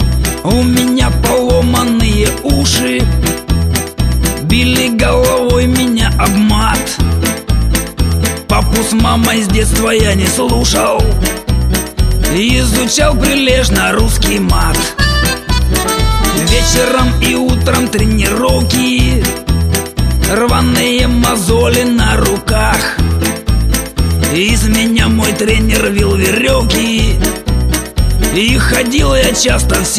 • Качество: 320, Stereo
мужской вокал
русский шансон
блатные